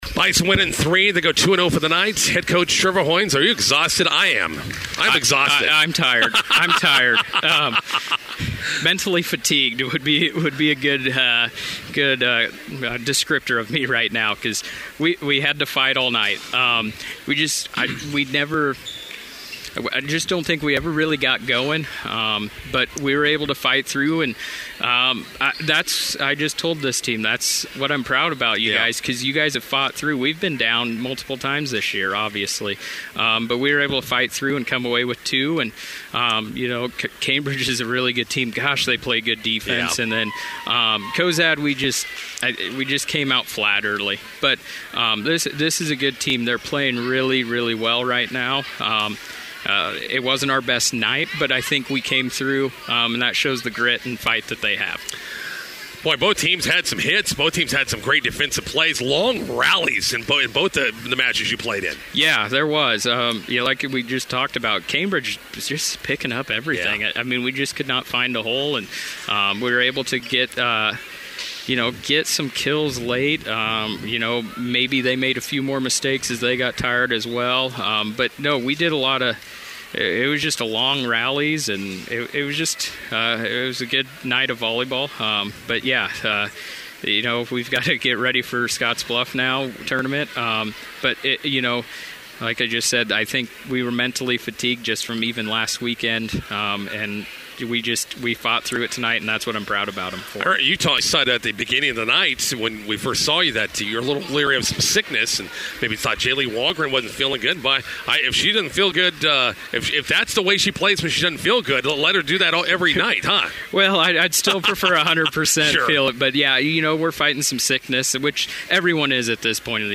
INTERVIEW: Bison volleyball pick up roads wins Cozad and Cambridge, prepare for two-day Twin Cities Invite this weekend.